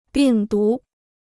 病毒 (bìng dú): virus.